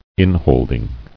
[in·hold·ing]